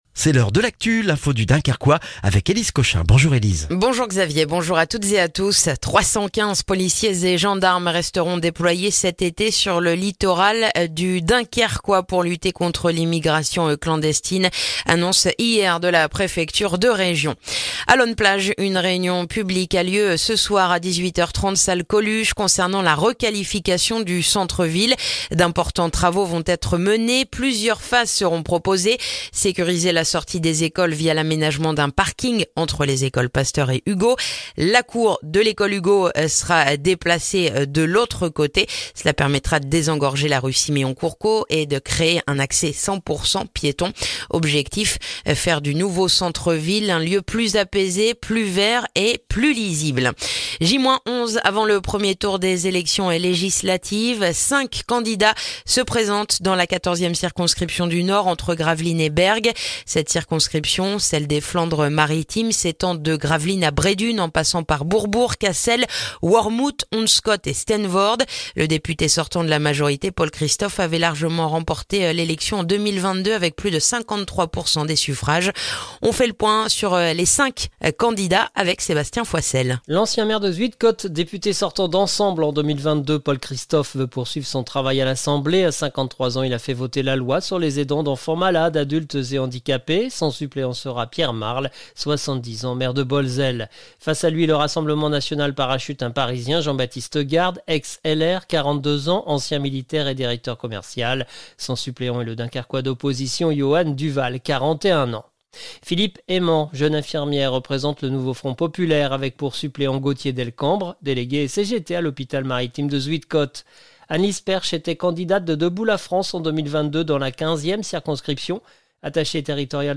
Le journal du mercredi 19 juin dans le dunkerquois